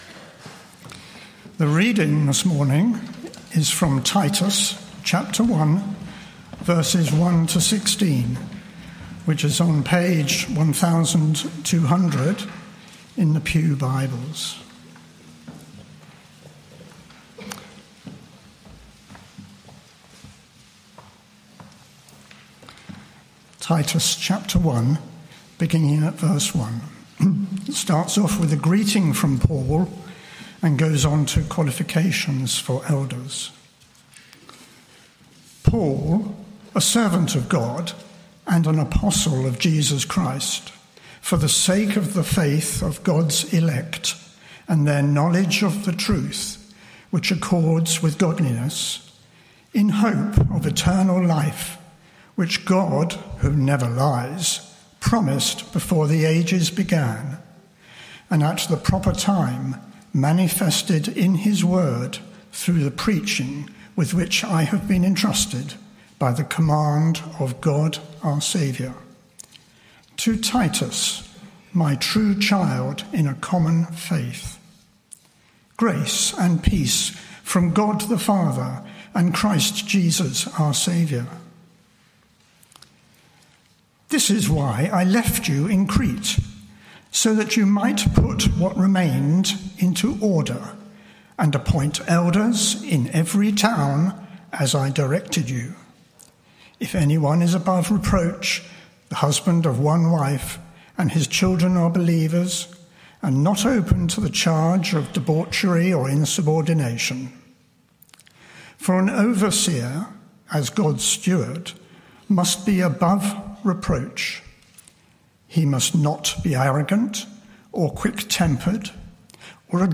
Media for Morning Meeting on Sun 29th Jun 2025 10:30 Speaker
AM Theme: Sermon Search media library...